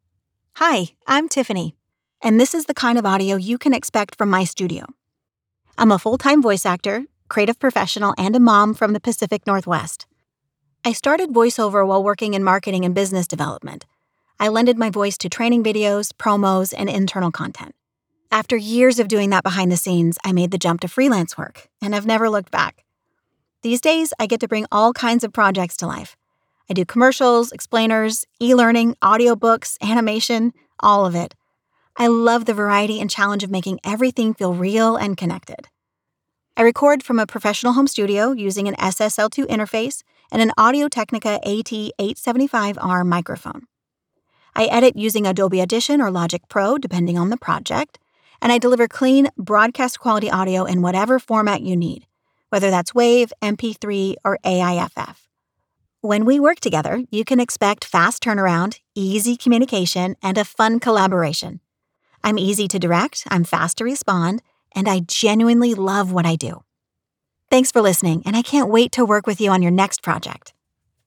Female
English (North American)
My voice is warm, clear, and approachable.
My delivery is natural, polished, and engaging, with strong instincts for pacing, tone, and emotional nuance.
Candid Studio Sample
0822StudioSample.mp3